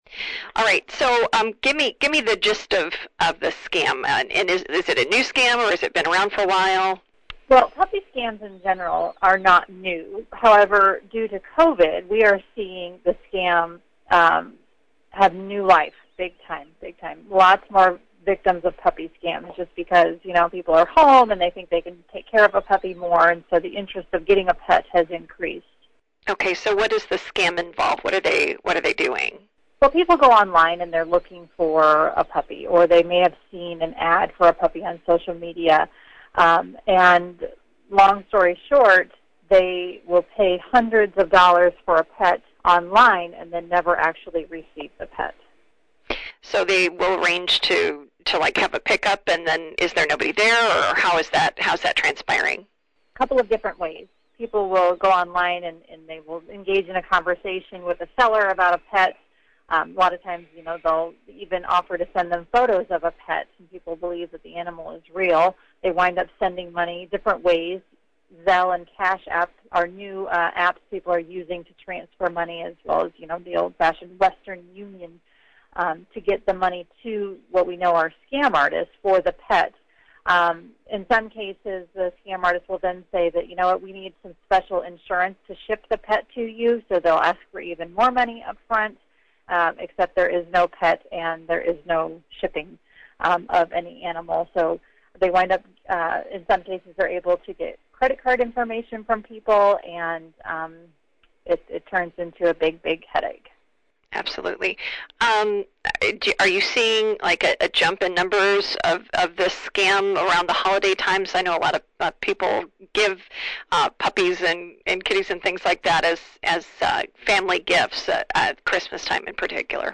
bbb-puppy-scam-full.mp3